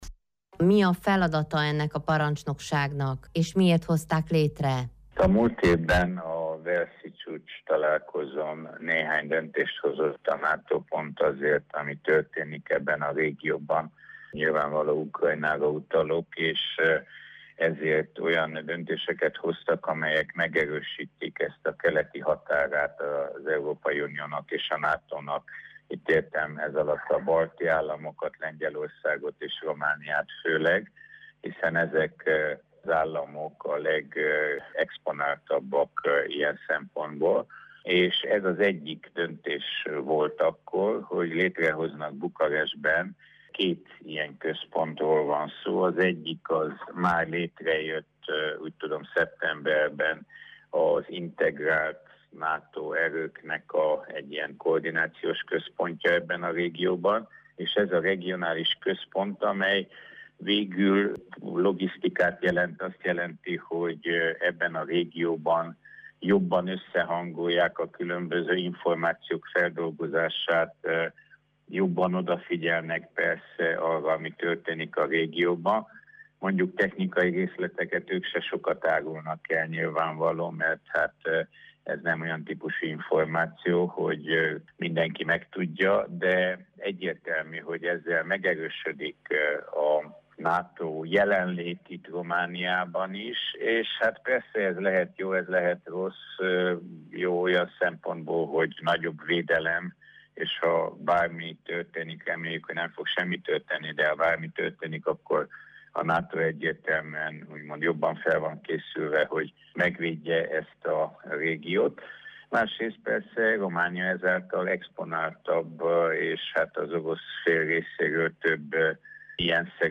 Megkezdte működését Bukarestben a szervezet regionális parancsnoksága. Borbély László parlamenti képviselőt, az Alsóház külügyi bizottságának elnökét kérdezzük.